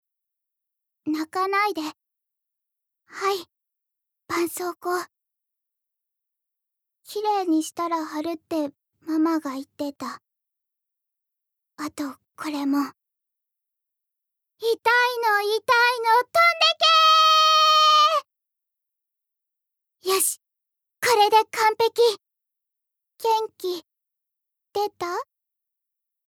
ボイスサンプル
セリフ３